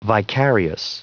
Prononciation du mot vicarious en anglais (fichier audio)